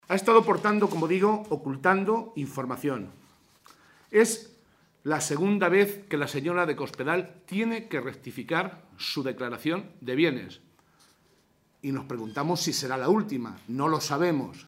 José Molina, portavoz del Grupo Parlamentario Socialista
Cortes de audio de la rueda de prensa